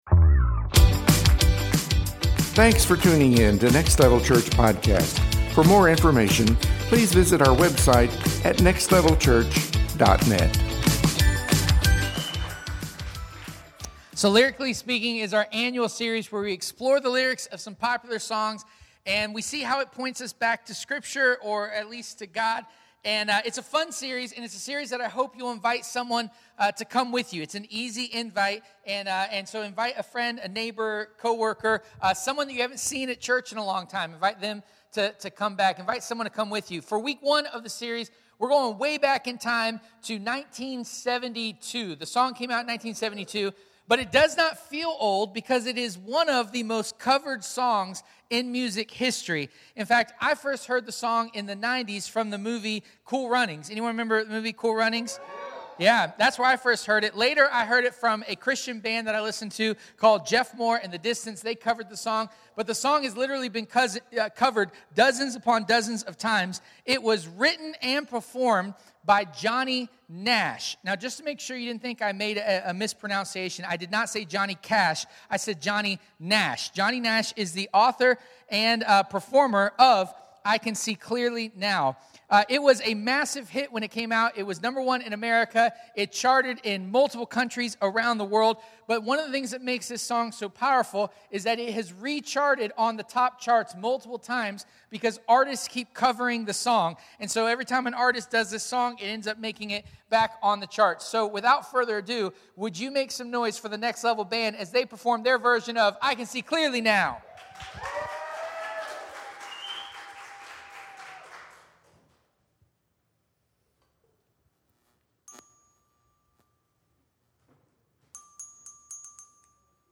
In this series our band will creatively play some popular songs to illustrate God’s truth.